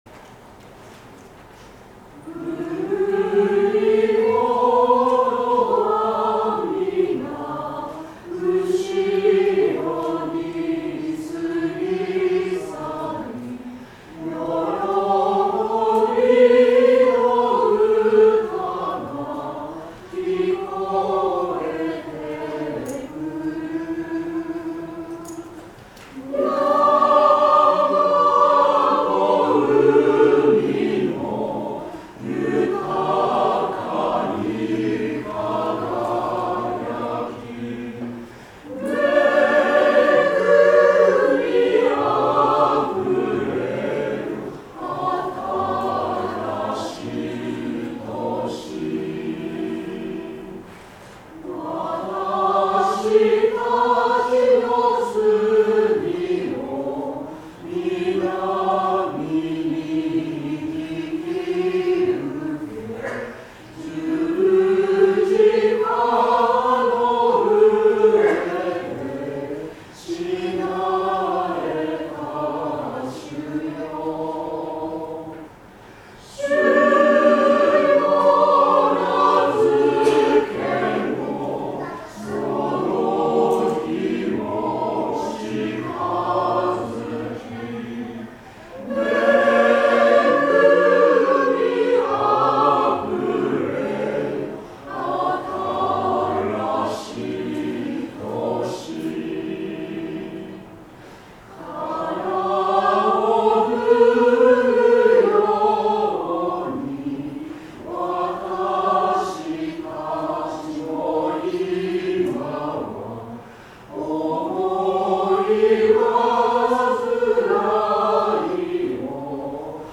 聖歌隊奉唱・献花